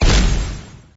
bomb.mp3